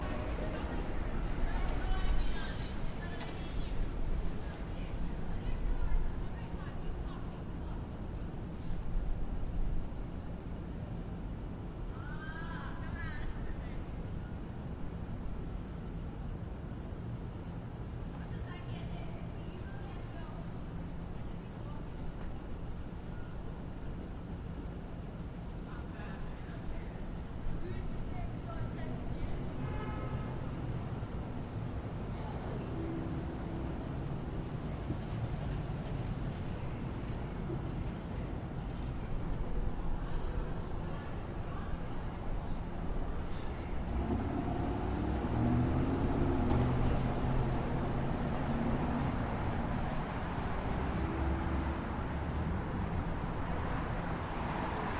纽约市的环境声音
描述：纽约市的环境声音。
Tag: 环境音 街道 一般噪音 气氛 交通 城市声音 实地录音 曼哈顿 声景 环境 背景音 城市 噪声 氛围 城镇 纽约